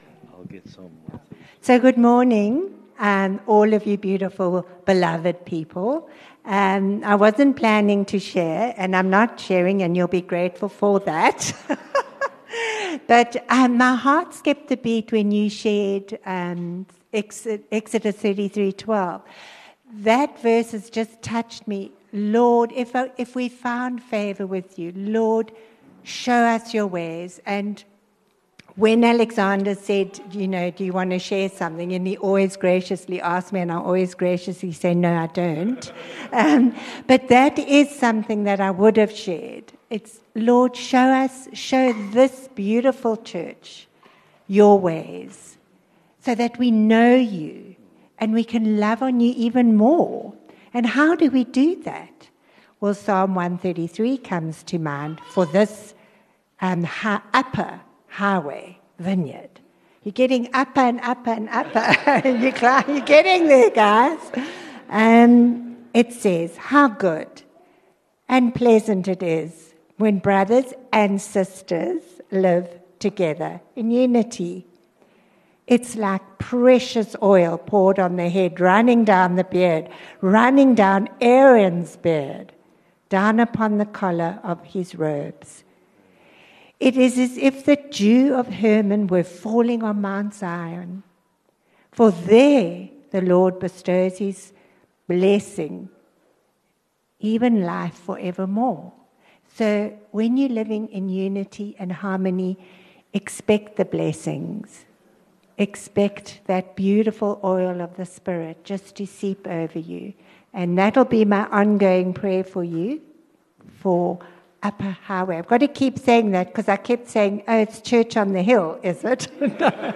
1 Sep Preach - 1 Sept 2024
View Promo Continue JacPod Install Upper Highway Vineyard Sunday messages 1 Sep Preach - 1 Sept 2024 54 MIN Download (25.5 MB) A New Beginning.